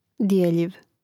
djèljiv djeljiv